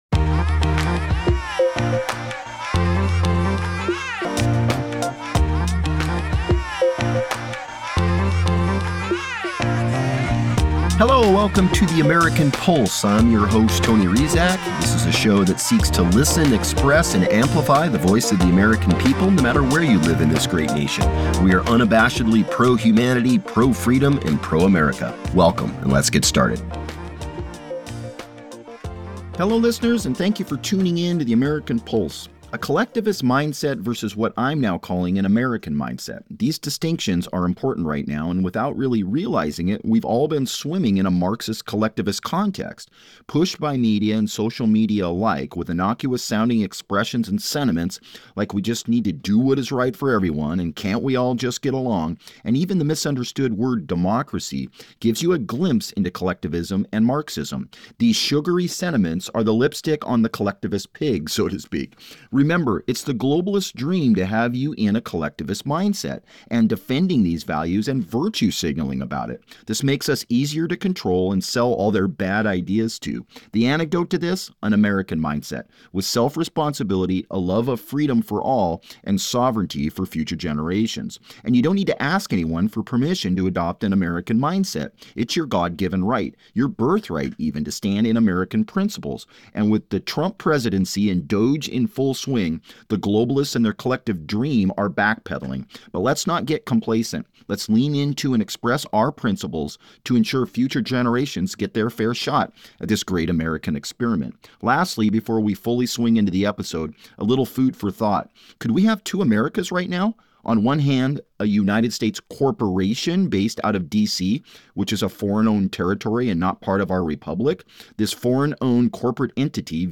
17 minute solo episode.